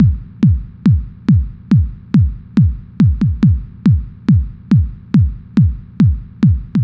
Kick 140-BPM 2.wav